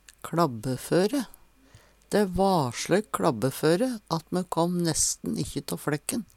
kLabbeføre - Numedalsmål (en-US)